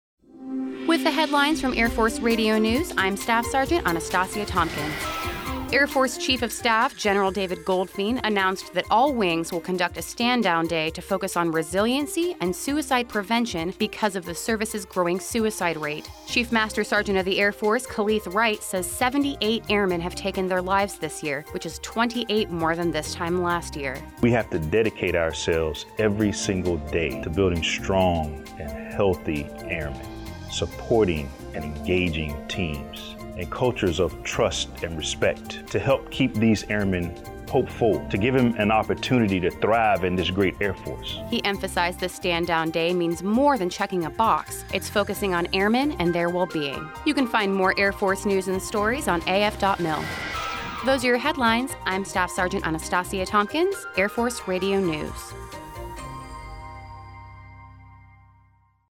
Air Force Radio News 02 August 2019